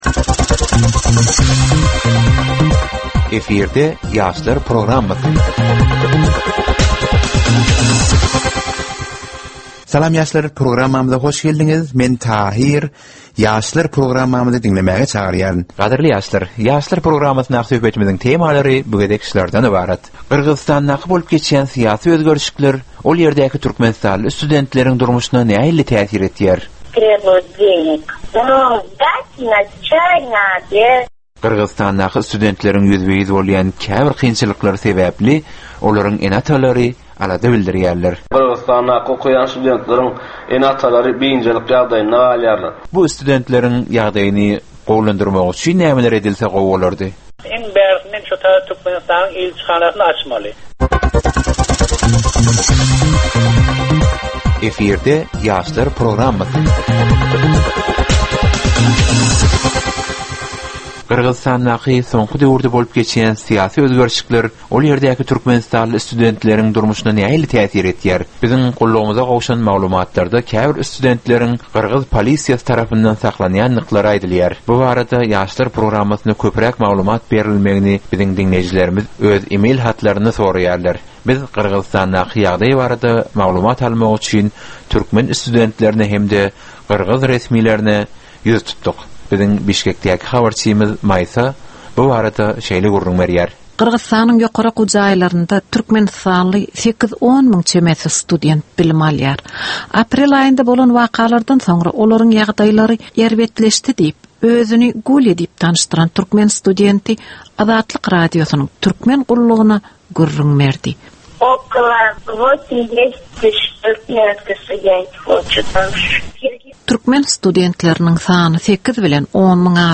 Türkmen we halkara yaşlarynyň durmuşyna degişli derwaýys meselelere we täzeliklere bagyşlanylyp taýýarlanylýan ýörite gepleşik.
Gepleşigiň dowamynda aýdym-sazlar hem eşitdirilýär.